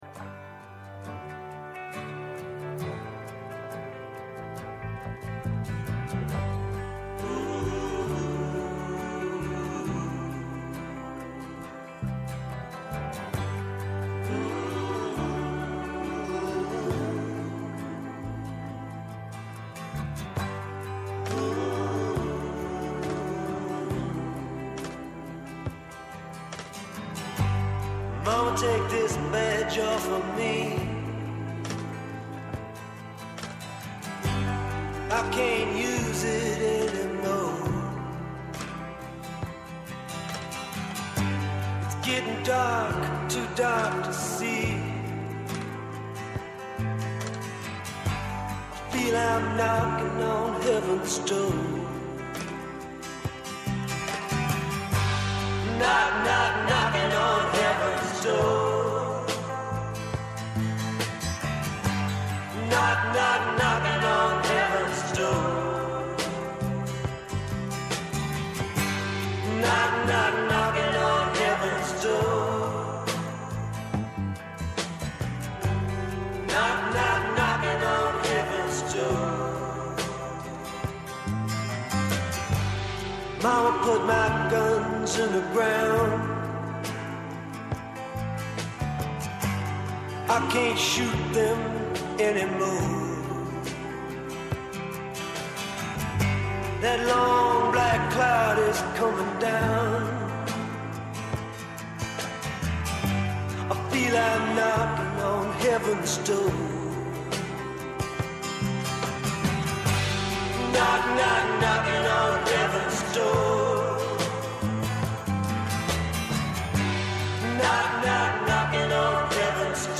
ψυχίατρος.